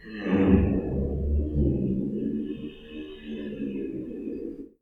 sounds_giraffe_hum.ogg